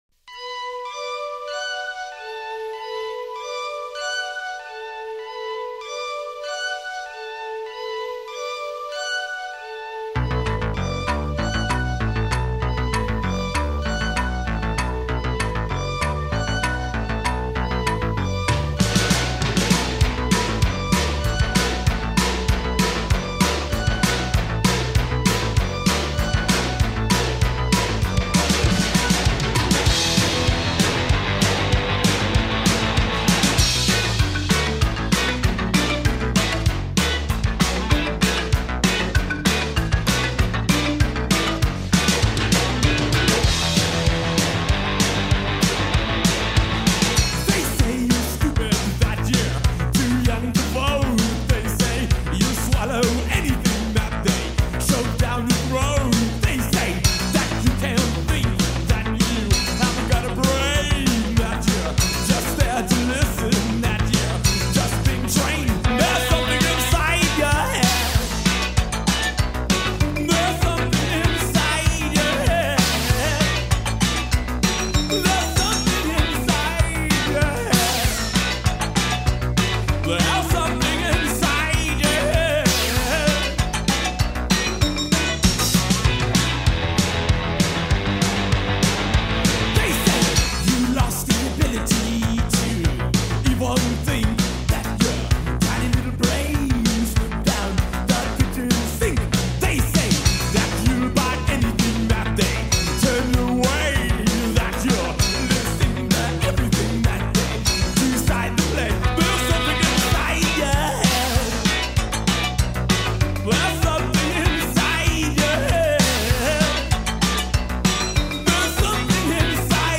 One of the best new wave bands of its time